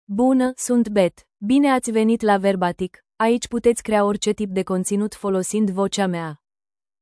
FemaleRomanian (Romania)
Beth is a female AI voice for Romanian (Romania).
Voice sample
Listen to Beth's female Romanian voice.
Female
Beth delivers clear pronunciation with authentic Romania Romanian intonation, making your content sound professionally produced.